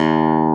CLAV G1+.wav